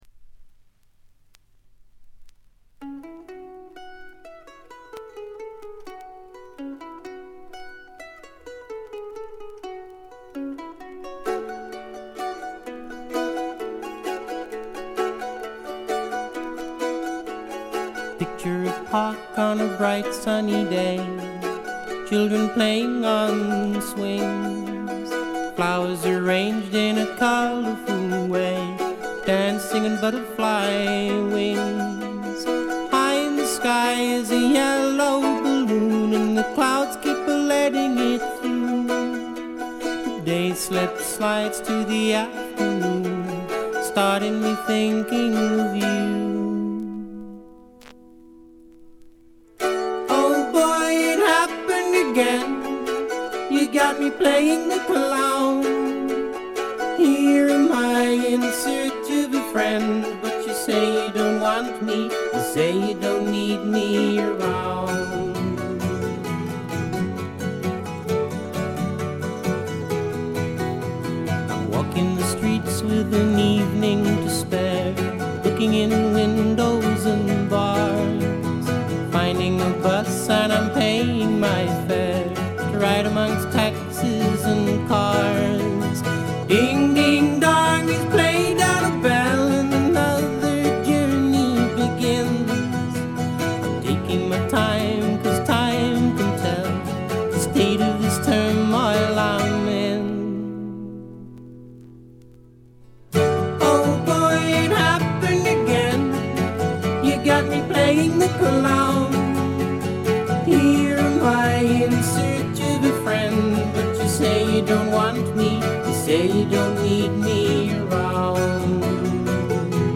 部分試聴ですが、軽微なノイズ感のみ。
やさしさにあふれた英国シンガー・ソングライターの好盤です。
試聴曲は現品からの取り込み音源です。
Recorded At - Morgan Studios